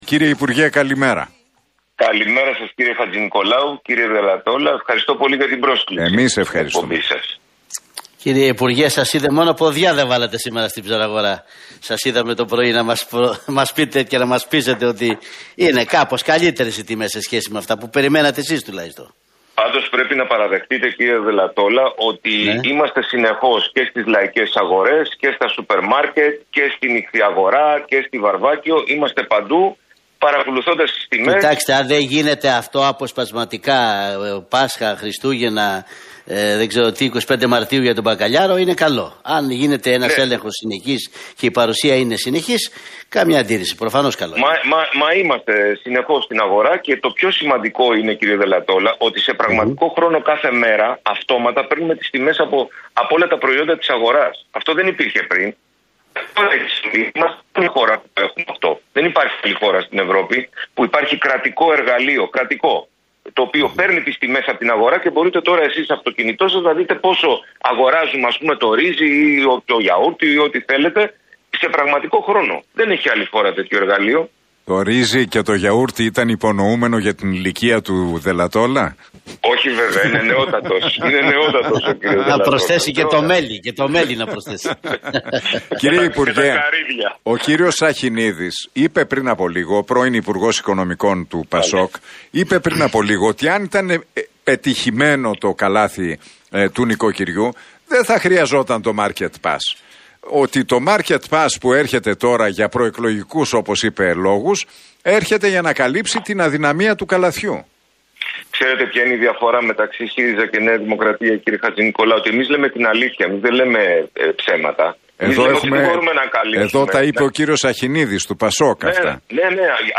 Παπαθανάσης στον Realfm 97,8: Έχουμε δημιουργήσει ανάχωμα με το καλάθι του νοικοκυριού